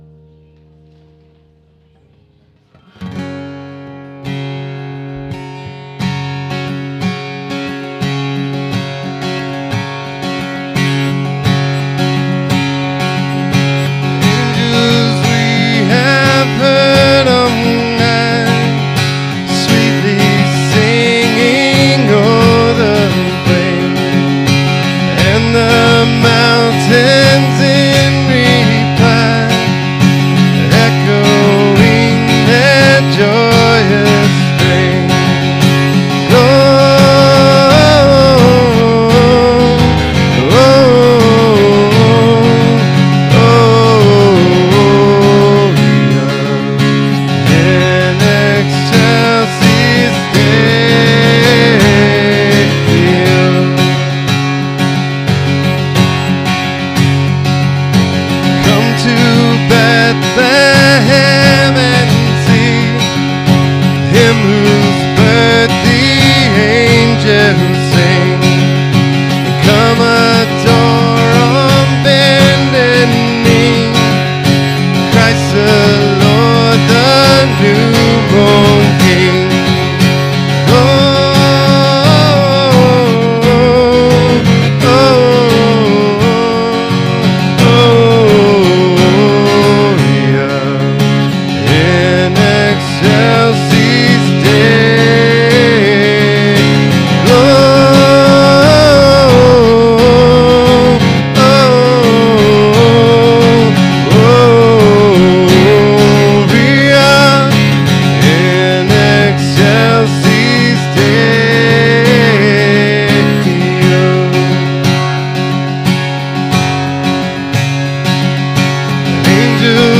SERMON DESCRIPTION The Christmas story is first and foremost God’s love story—a divine announcement of good news, great joy, and peace for all people through the birth of a Savior.